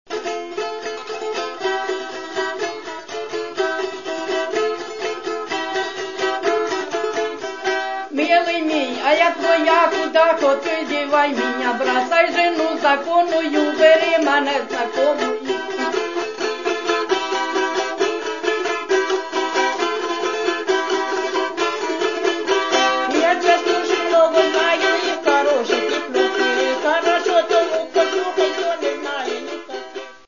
приспівки